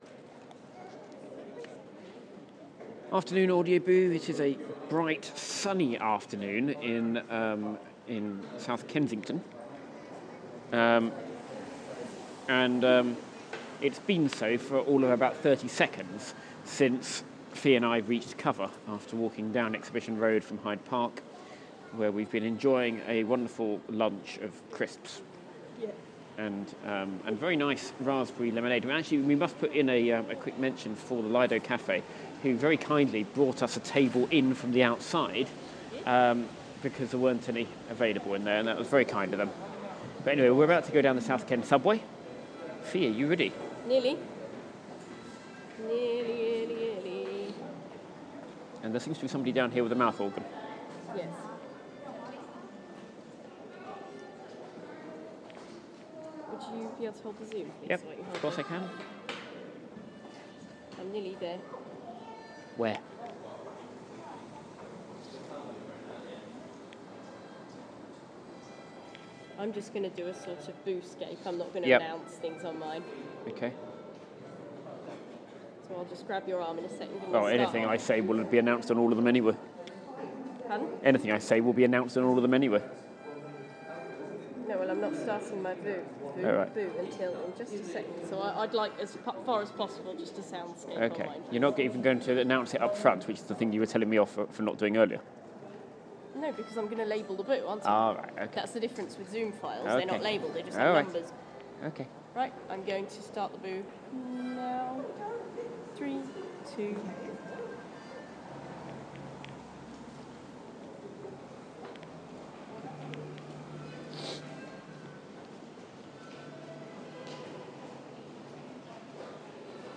A distinct lack of busking in the South Kensington subway